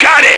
Index of /action/sound/radio/male